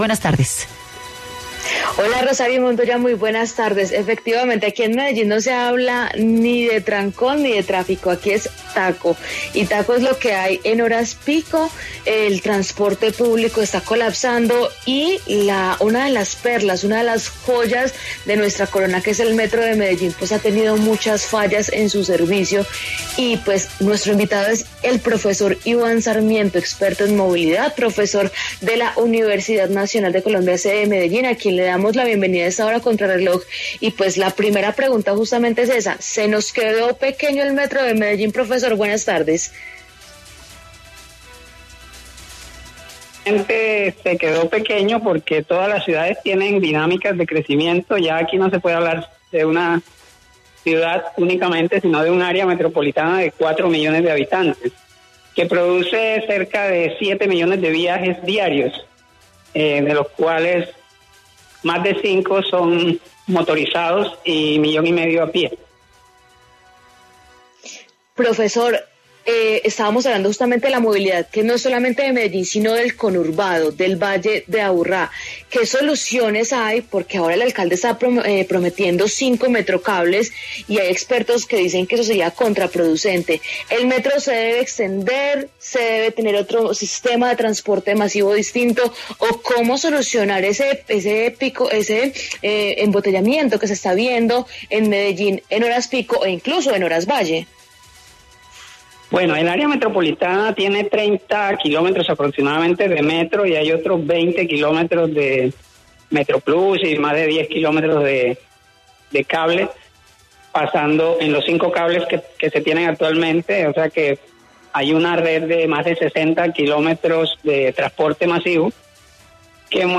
¿Cuáles son las razones de las demoras en el tráfico de Medellín? Experto responde